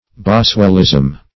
Boswellism \Bos"well*ism\, n. The style of Boswell.
boswellism.mp3